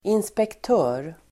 Uttal: [inspekt'ö:r]